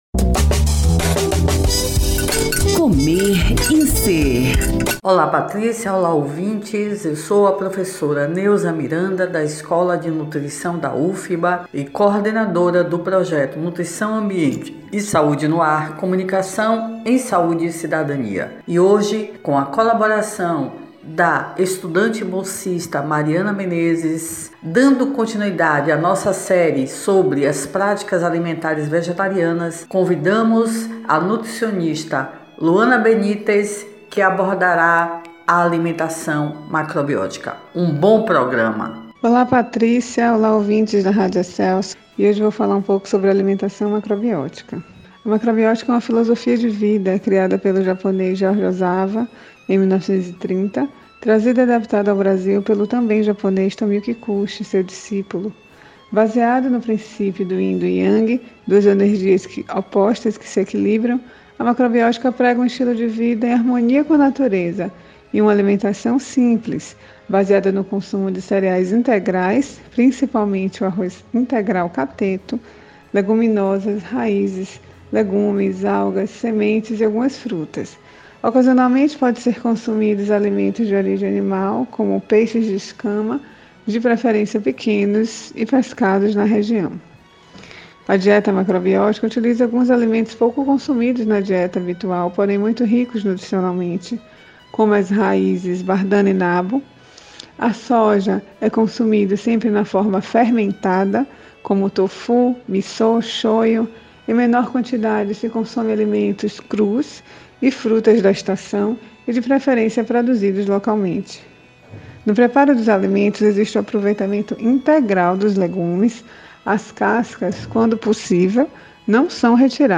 O assunto foi tema do quadro “Comer e Ser”, veiculado às Segundas-feiras pelo programa Saúde no ar, com transmissão pelas Rádios Excelsior AM 840  e  Web Saúde no ar.